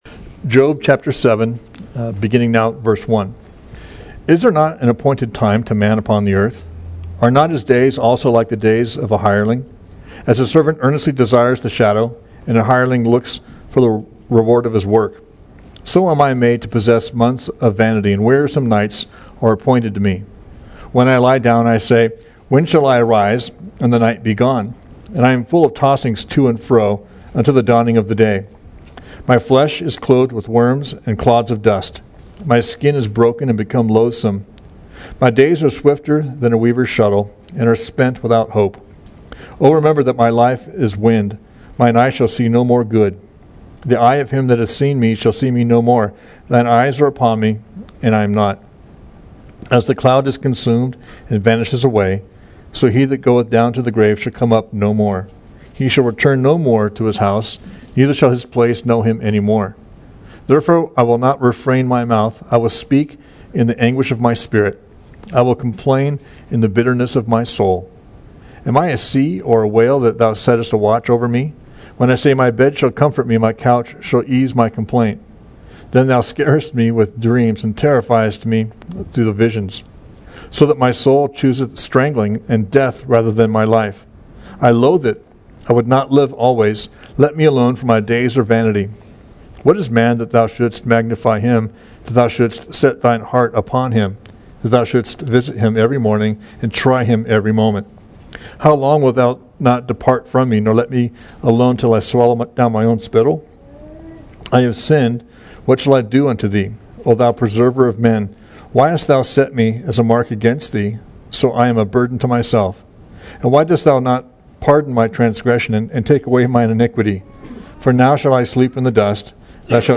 We livestream all of our services on FaceBook.